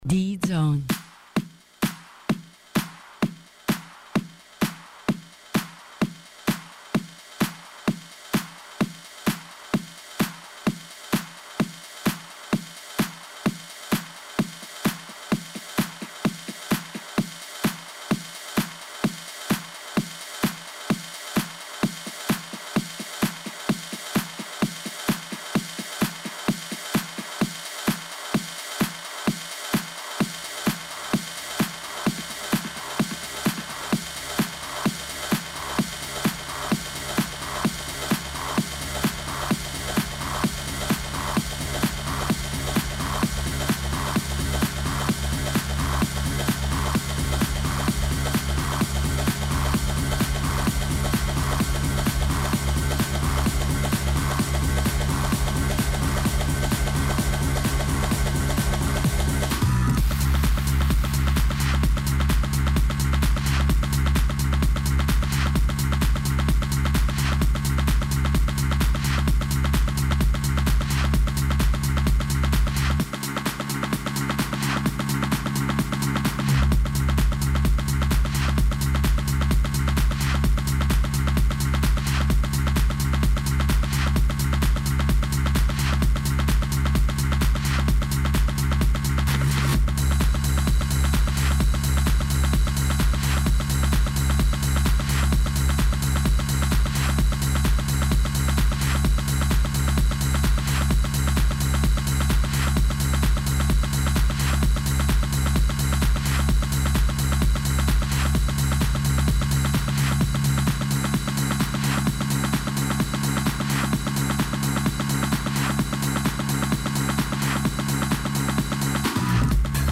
ON-AIR LIVE
Guest tonight live on his set in exclusive preview
techno